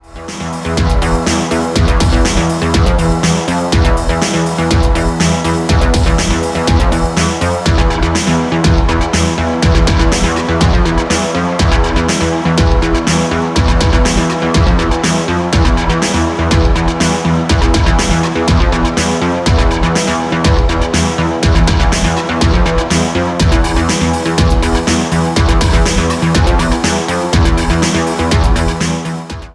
'Berlinisch' Techno EBM from Italy